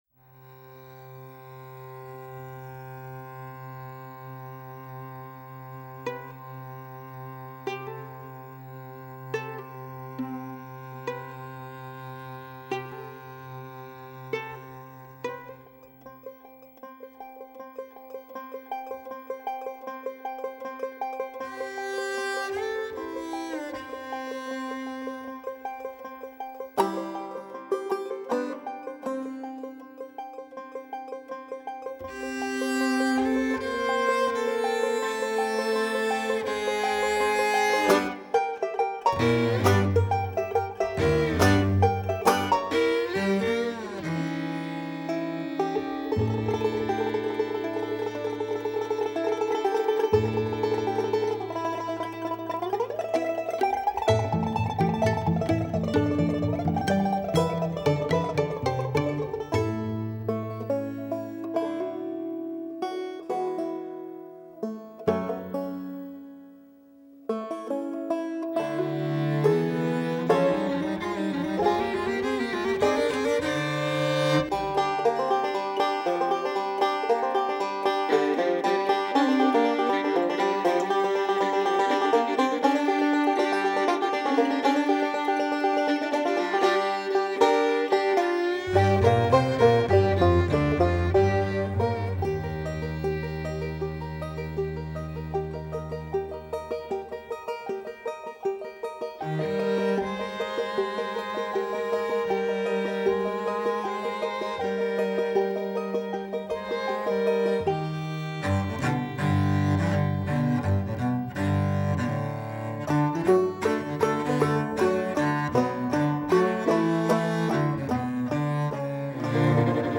overture that kicks off the album.